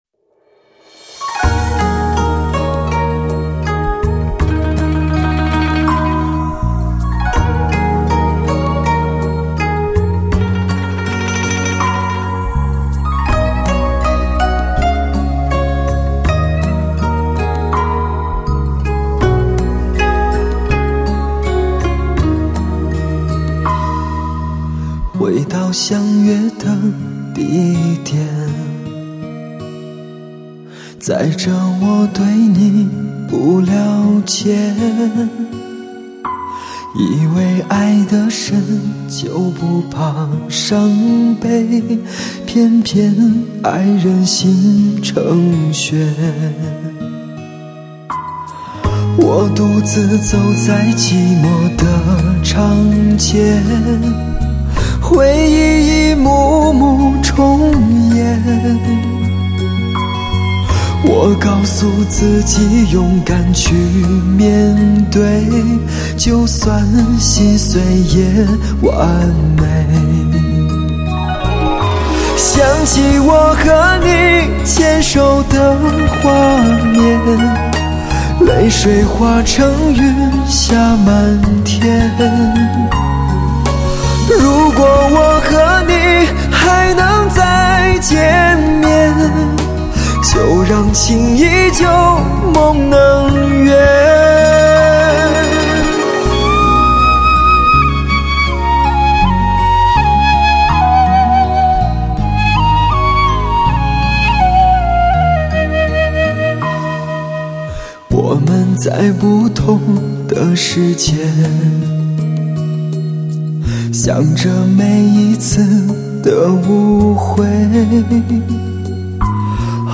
满天纷飞的忧伤旋律，重演的伤感